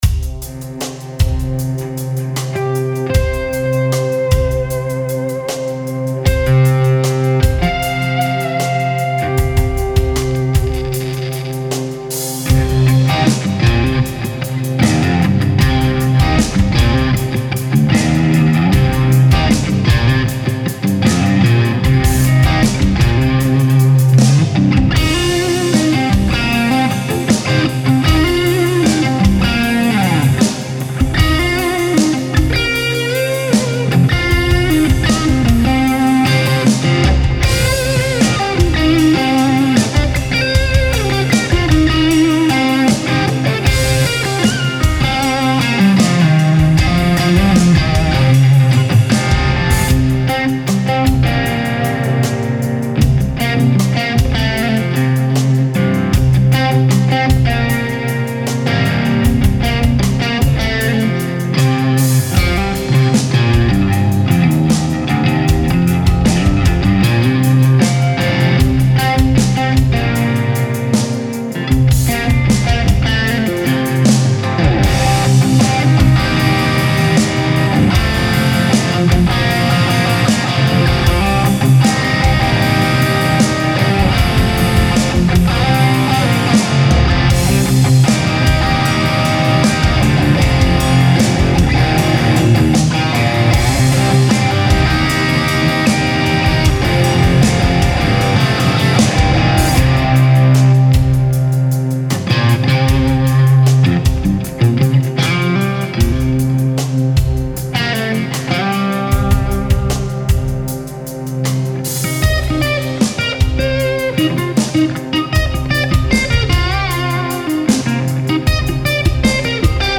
бедный SRV)) а куда столько ревера )?)) что вы им там замаскировать пытаетесь )?)
Может кто-то может продемонстрировать подобное на плагинах или современных процах (кемпер, акс), как пример выкладываю запись через классическую схему гитара - стек - микрофон - звуковуха Вложения Marshall Hiwatt+.mp3 Marshall Hiwatt+.mp3 5,7 MB · Просмотры: 491